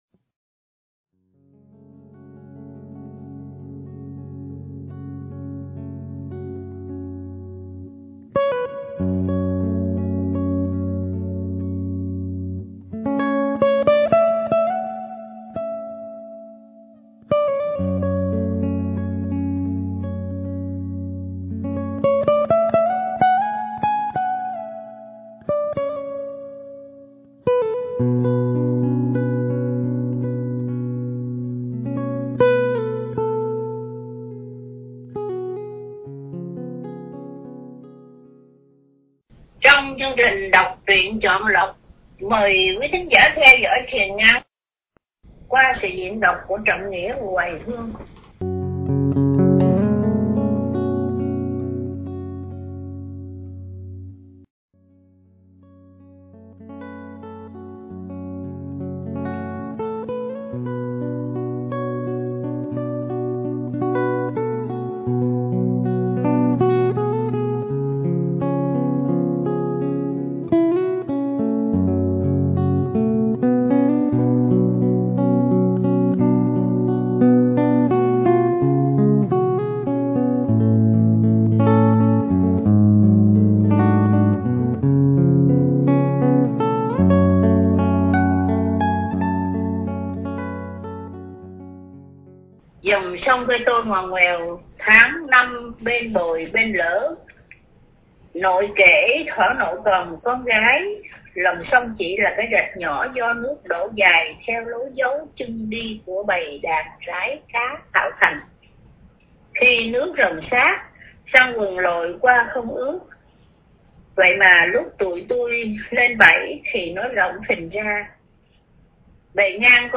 Đọc Truyện Chọn Lọc – Truyện Ngắn “Vầng Trăng Bên Sông” – Sông Cửu – Radio Tiếng Nước Tôi San Diego